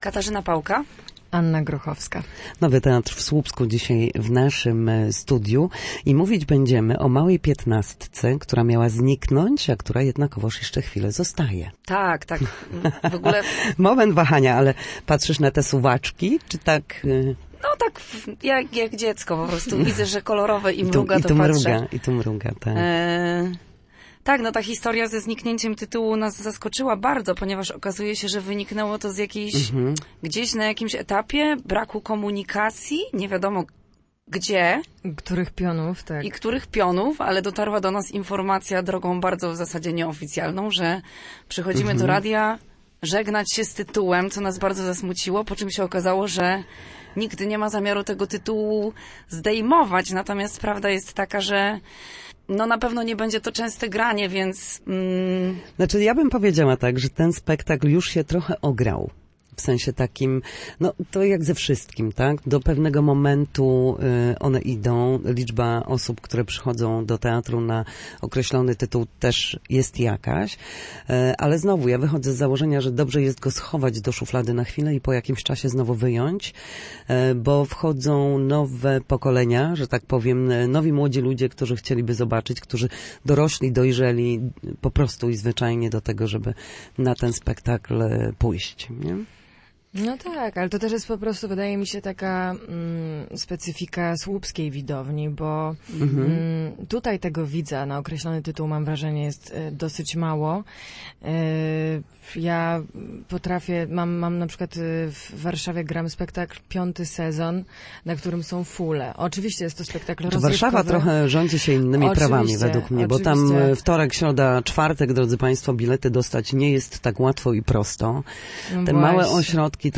W Studiu Słupsk aktorki Nowego Teatru